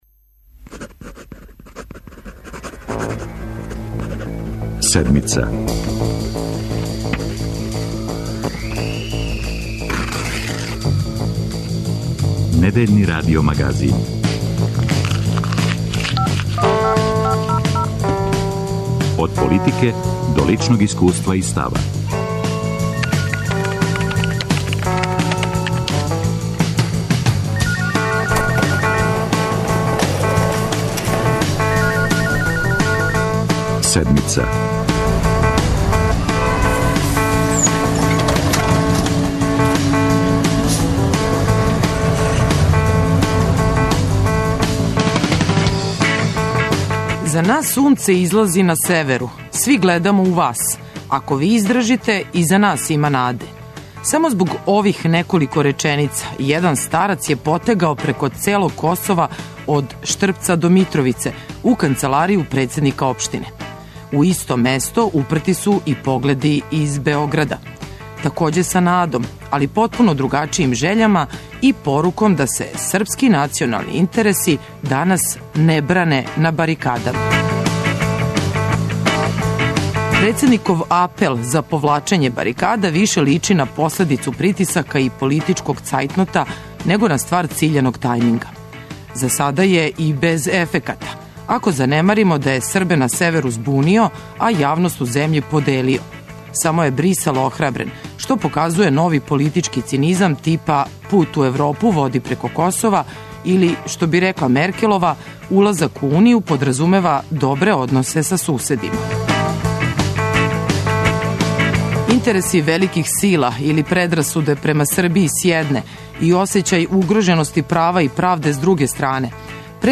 Председник Тадић позвао је Србе да се повуку са барикада. Какав ће њихов одговор бити, за Седмицу говори председник општине Косовска Митровица Крстимир Пантић.